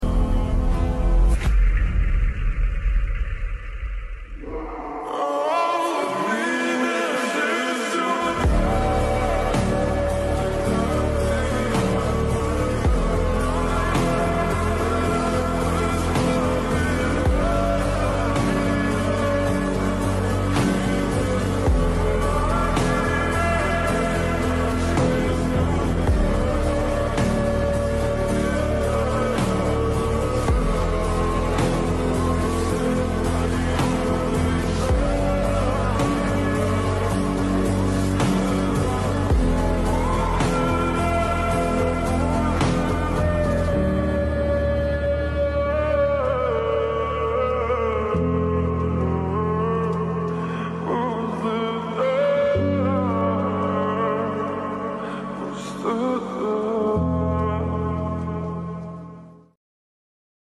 The great white maned lion 😱😱 sound effects free download